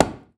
WoodFall0.wav